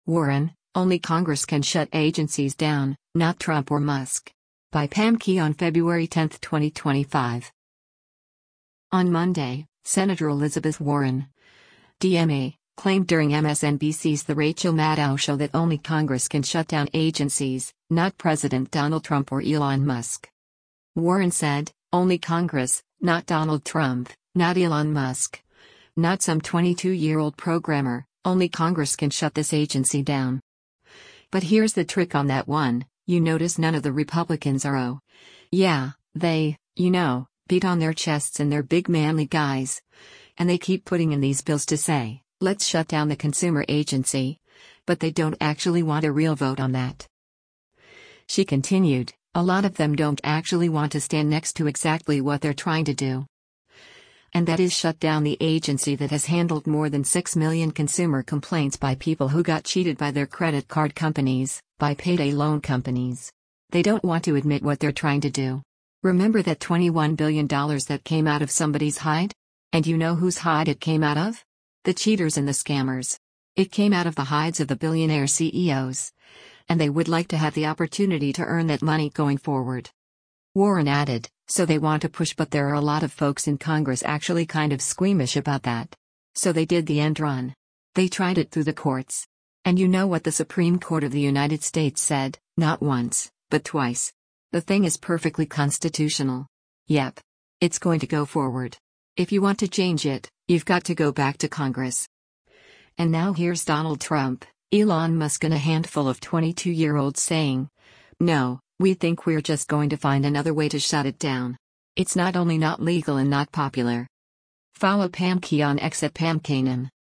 On Monday, Sen. Elizabeth Warren (D-MA) claimed during MSNBC’s “The Rachel Maddow Show” that only Congress can shut down agencies, not President Donald Trump or Elon Musk.